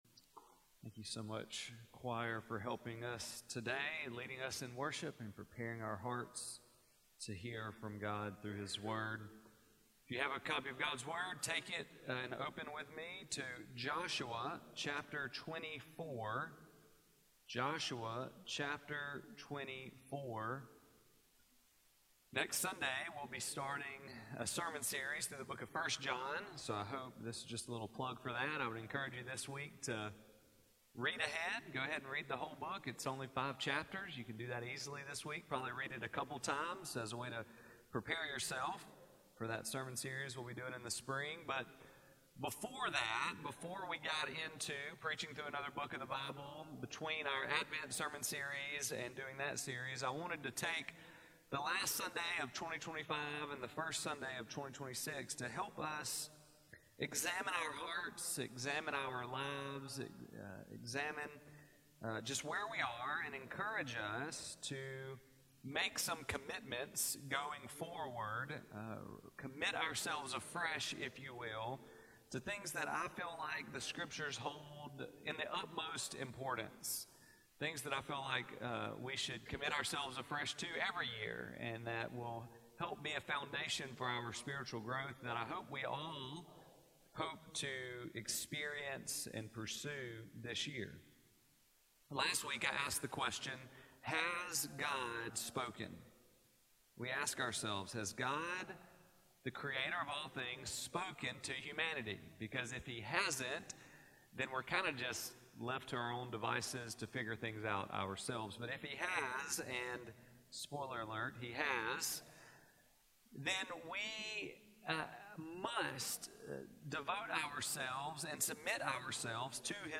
January 4, 2026 Sermon Audio.mp3